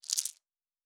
Dice Shake 4.wav